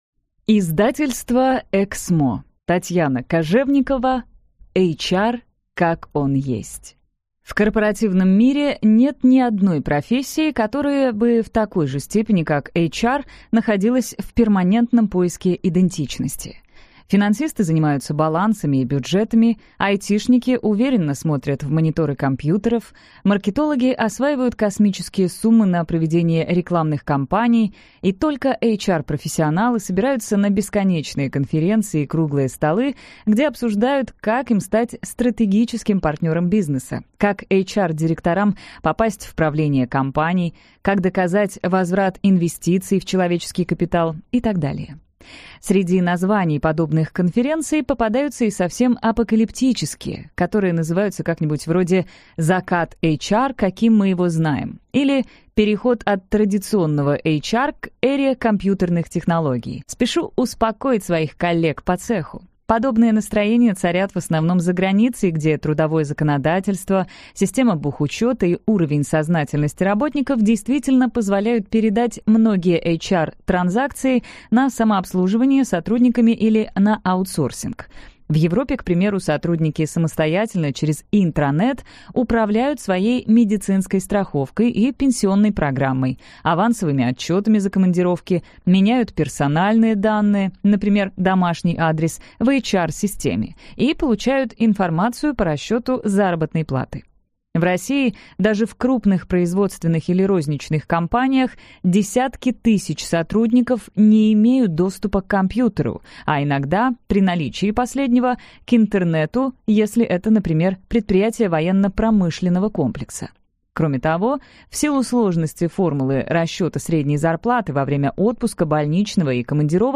Аудиокнига HR как он есть | Библиотека аудиокниг